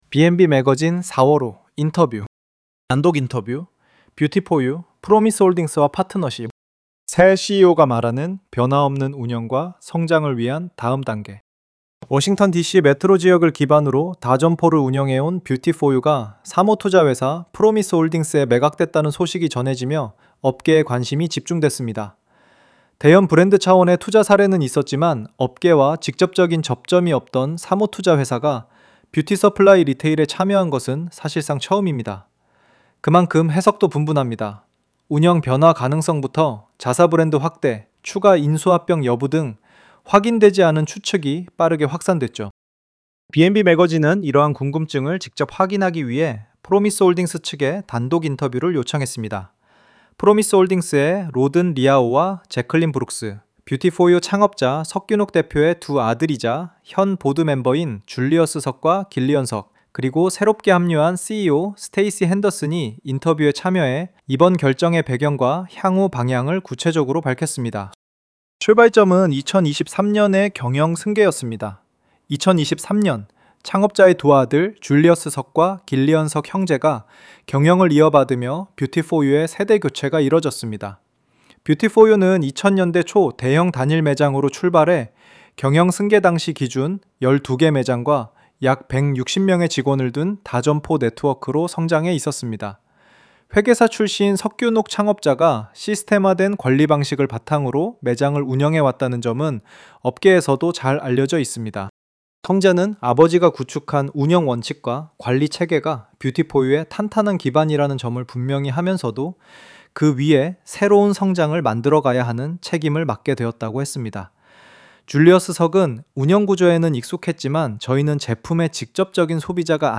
03_Interview_뷰티포유_인터뷰.mp3